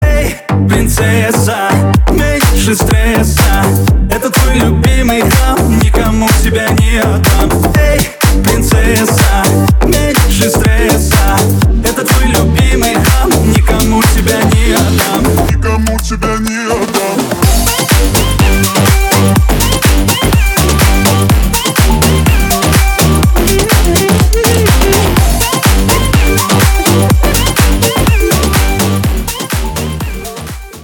Ремикс
кавказские